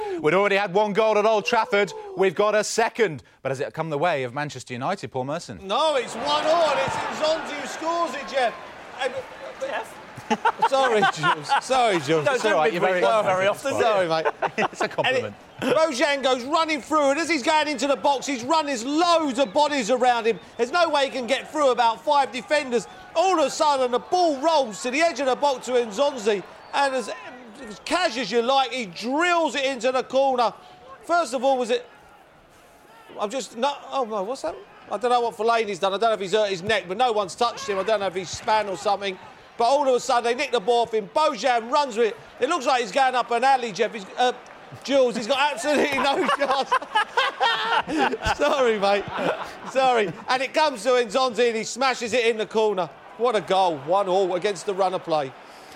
While reporting on Manchester United v Stoke for Soccer Special